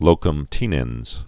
(lōkəm tēnĕnz, tĕnənz)